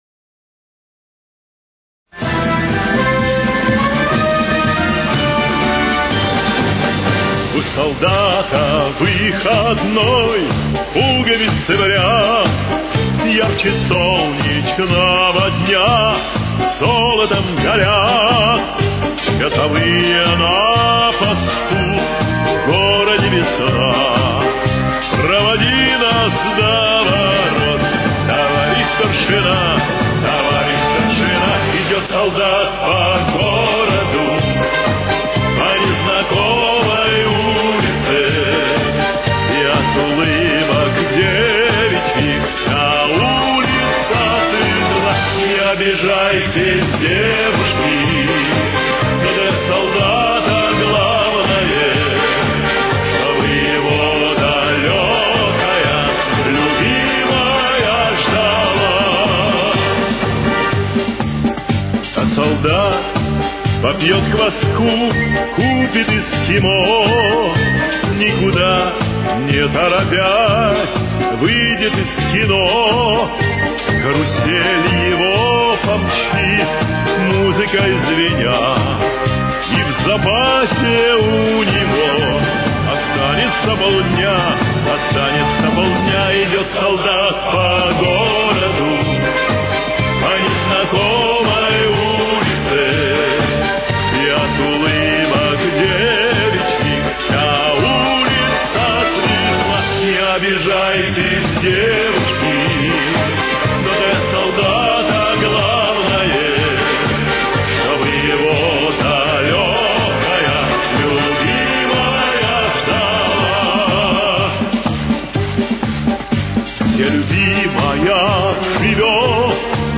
Темп: 126.